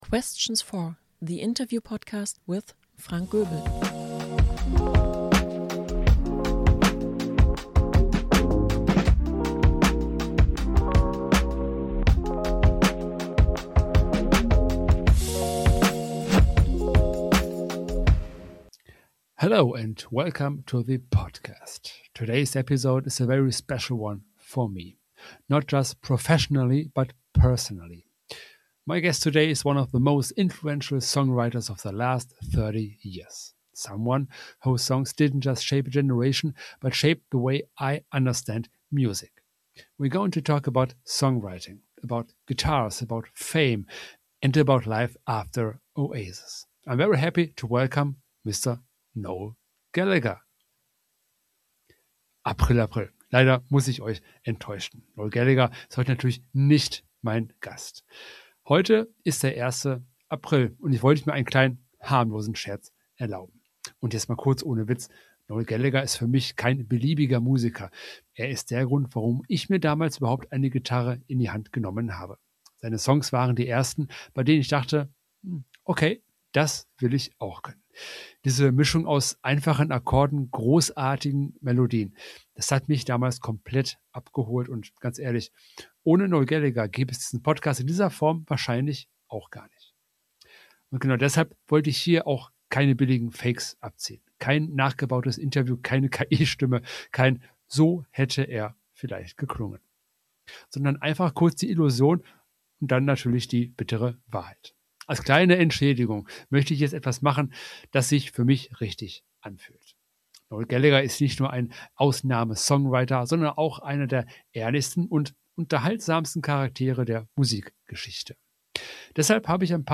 Der Interview-Podcast